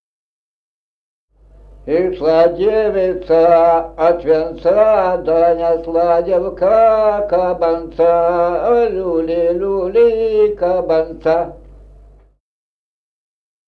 Музыкальный фольклор села Мишковка «Ишла девица от венца», свадебная, репертуар скрипача.